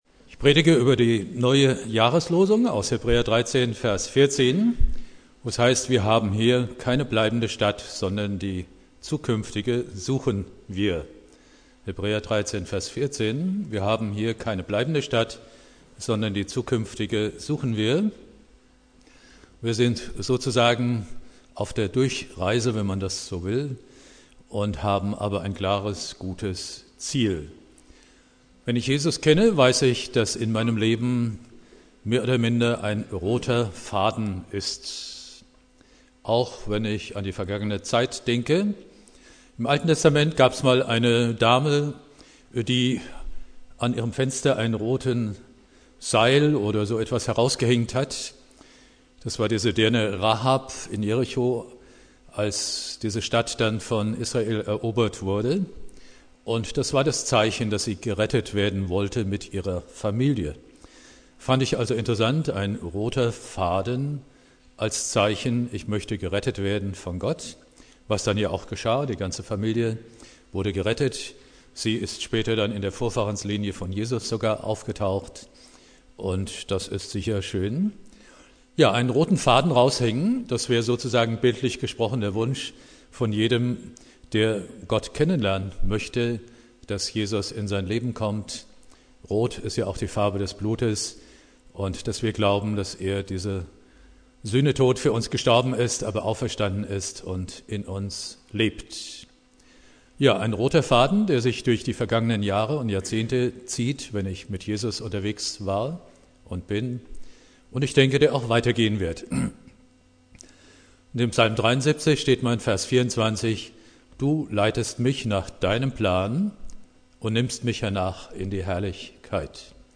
Predigt
Neujahr